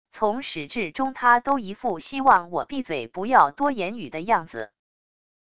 从始至终他都一副希望我闭嘴不要多言语的样子wav音频生成系统WAV Audio Player